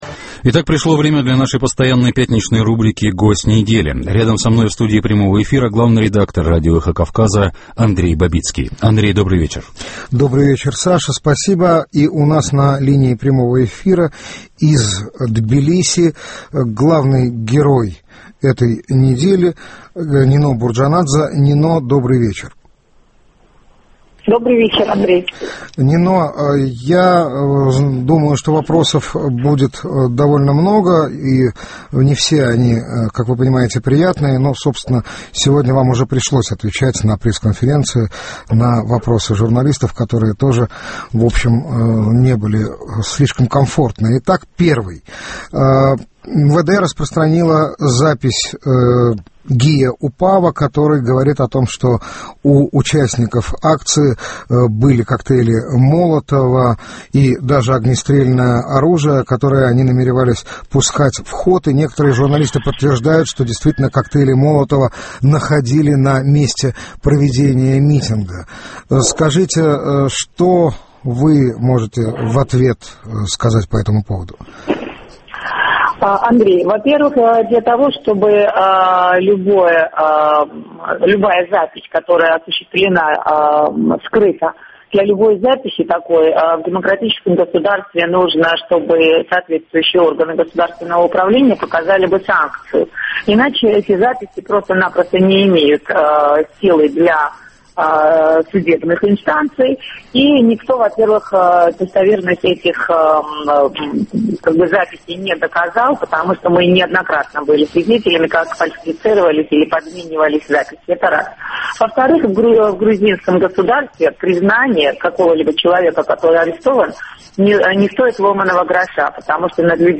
В прямом эфире радио "Эхо Кавказа" один из лидеров грузинской оппозиции Нино Бурджанадзе.